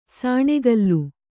♪ sāṇegallu